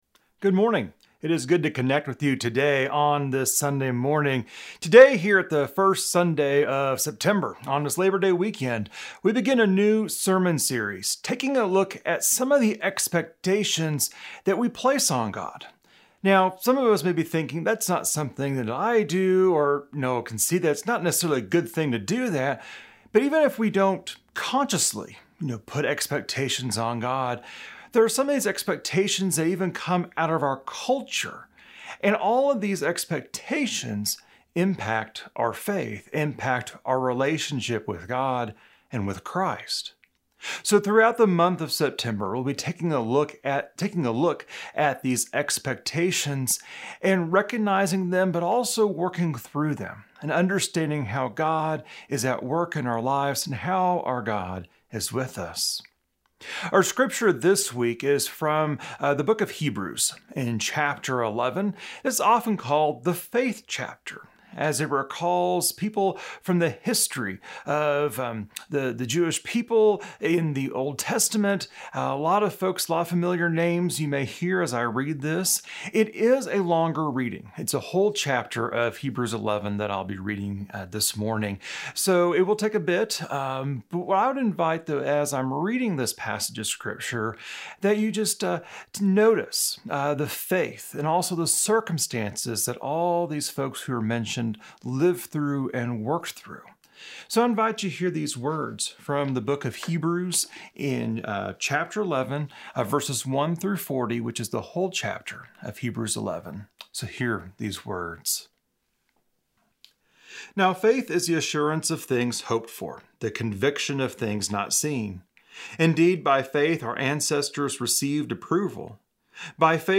September-6-Sermon-Audio.mp3